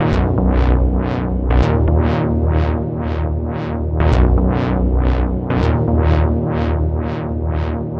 TSNRG2 Bassline 024.wav